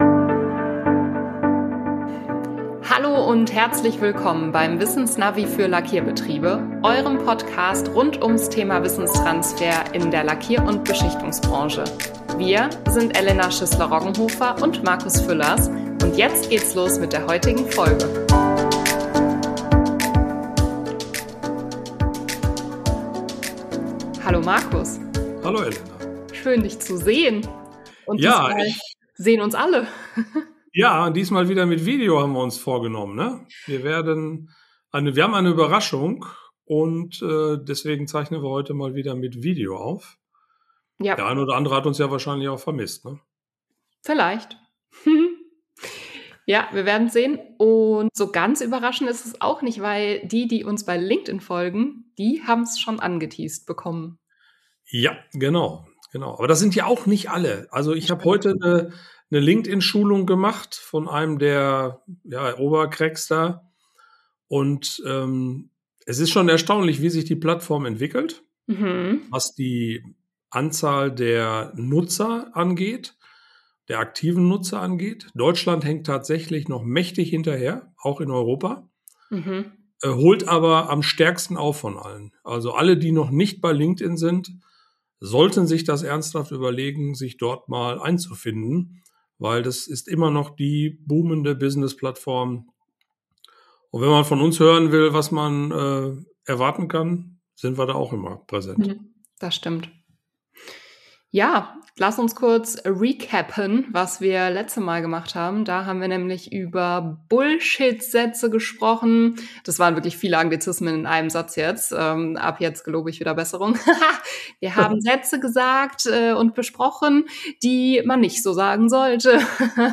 Wir sprechen offen darüber, wie schnell wertvolles Wissen verloren gehen kann – und wie wir gemeinsam verhindern, dass das Rad im Betrieb immer wieder neu erfunden werden muss. Mit einer Prise Humor, persönlichen Geschichten und einem Live-Pecha-Kucha-Vortrag zeigen wir, wie strukturierte Methoden Licht ins Wissensdunkel bringen können. Ihr erfahrt, warum Motivation, Zeit und die richtige Infrastruktur entscheidend sind, damit Know-how nicht in den Köpfen Einzelner verschwindet.